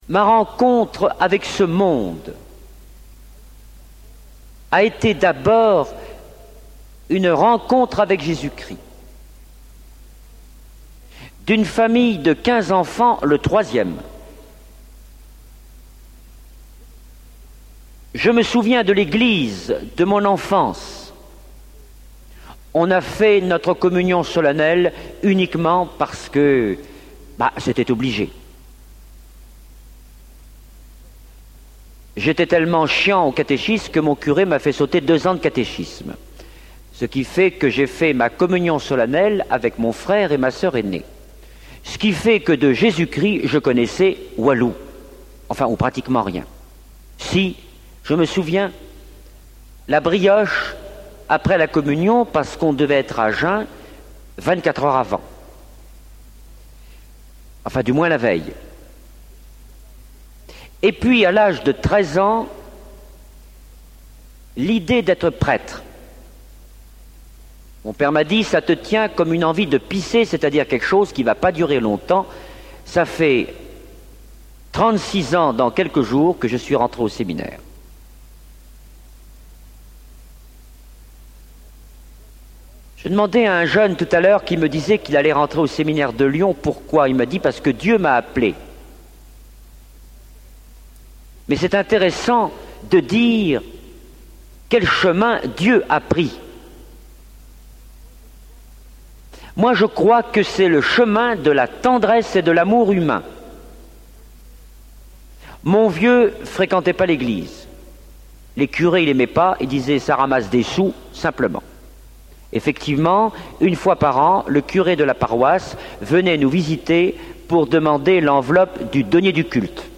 T�moignage de Guy Gilbert [ P.Guy Gilbert R�f: E002419 Produit original: Ateliers Chemin Neuf ACN511] - 3.00 EUR :